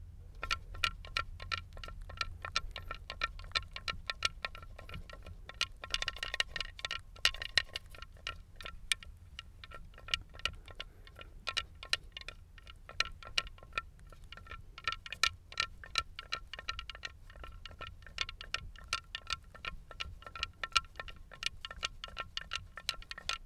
Audio 1. Plato de cerámica con soporte trípode de sonajas, estilo Danzante de Tikal, entierro 45 de estructura 5N/6 Sub, Complejo Amurallado del Grupo A (fig. 5a).